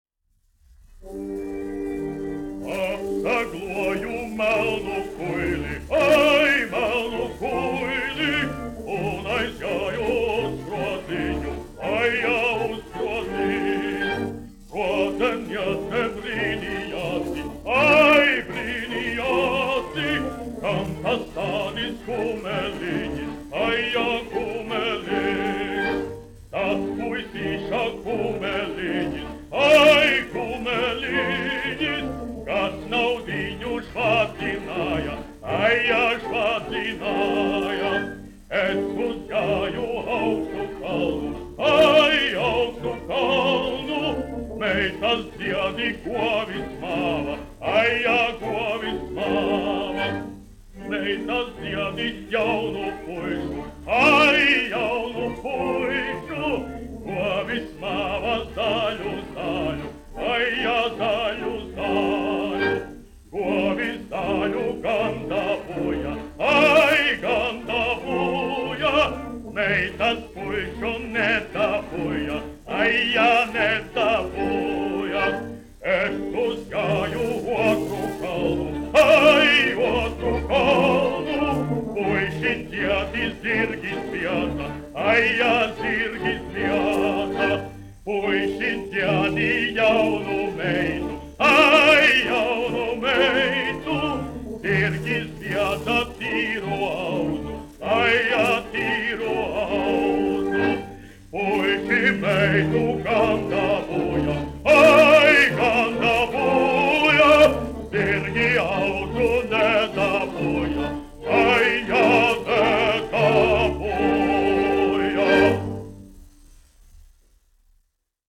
Jāzeps Vītols, 1863-1948, aranžētājs
Kaktiņš, Ādolfs, 1885-1965, dziedātājs
1 skpl. : analogs, 78 apgr/min, mono ; 25 cm
Latviešu tautasdziesmas
Dziesmas (vidēja balss) ar orķestri
Latvijas vēsturiskie šellaka skaņuplašu ieraksti (Kolekcija)